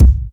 Mpckik.wav